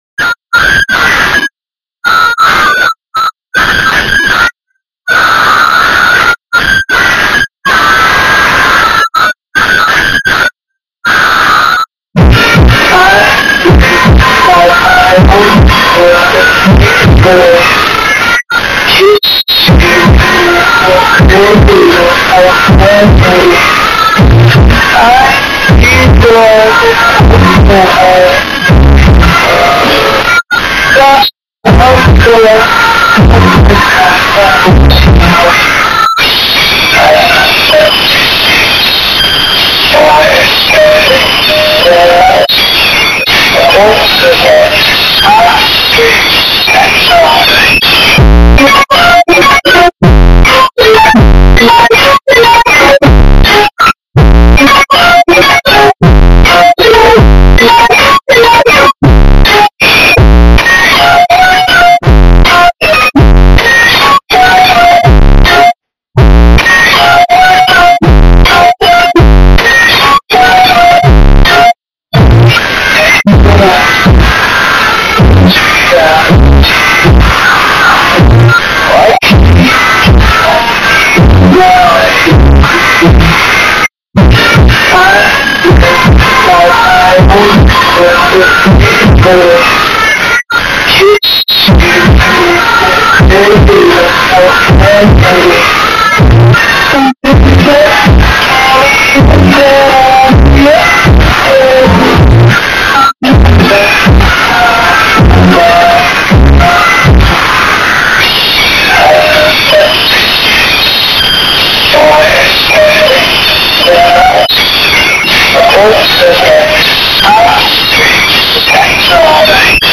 at very low quality